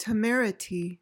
PRONUNCIATION:
(tuh-MER-i-tee)